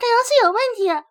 error.ogg